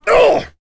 Kaulah-Vox_Attack3.wav